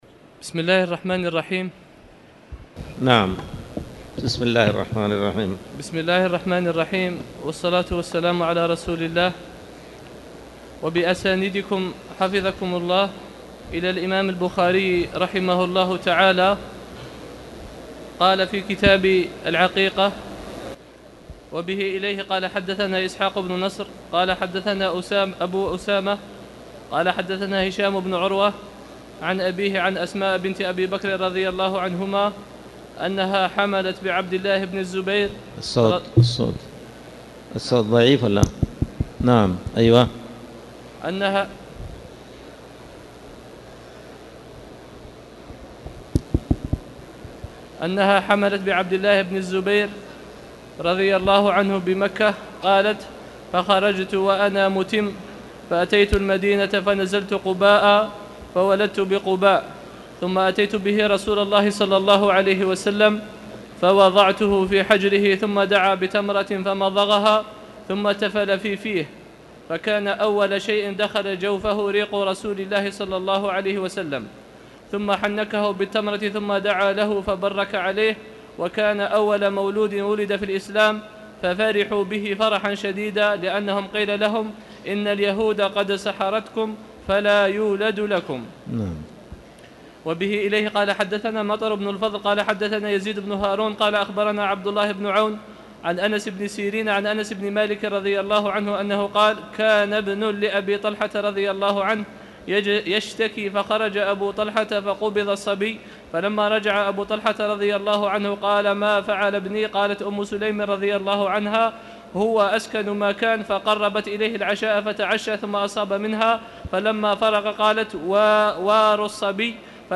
تاريخ النشر ١٨ ربيع الأول ١٤٣٨ هـ المكان: المسجد الحرام الشيخ